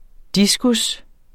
Udtale [ ˈdisgus ]